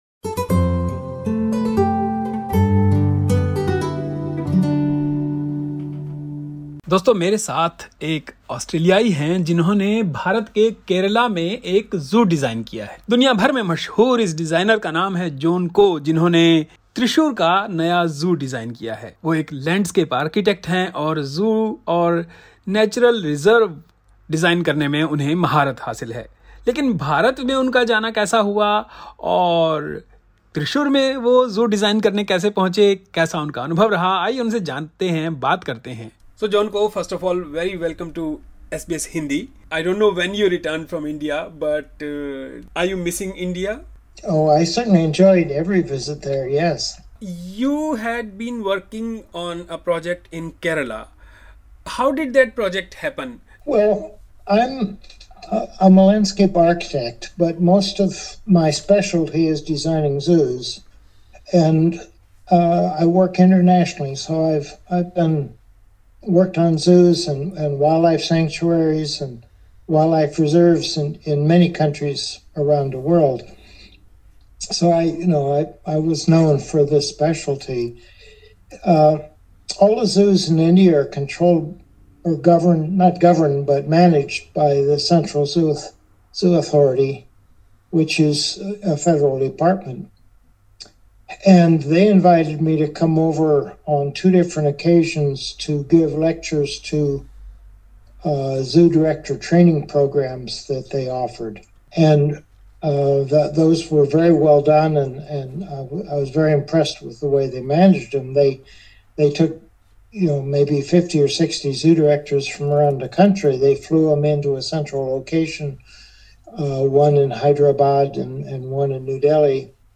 Listen to an insightful conversation